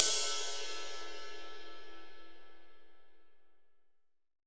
Index of /90_sSampleCDs/AKAI S6000 CD-ROM - Volume 3/Ride_Cymbal2/JAZZ_RIDE_CYMBAL